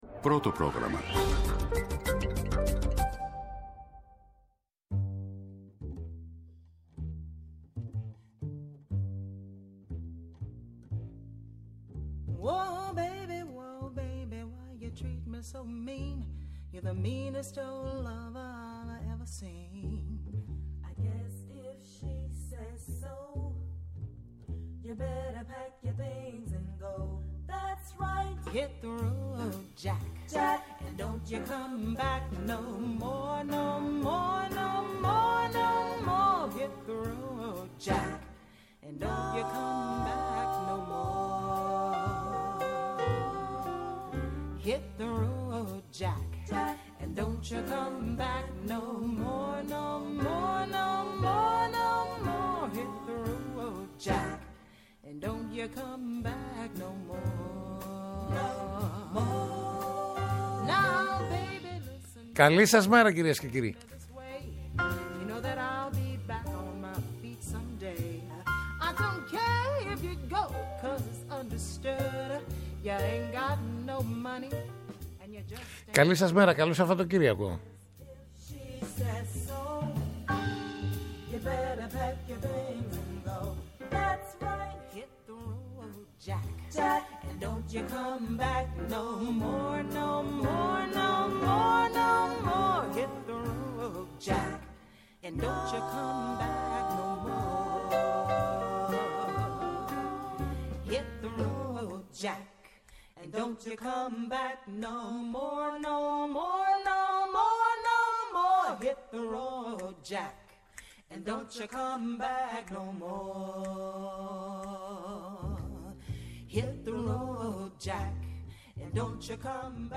-Η εκπρόσωπος Τύπου της Νέας Δημοκρατίας Αλεξάνδρα Σδούκου, για τη μονή Σινά, και την προανακριτική για τα Τέμπη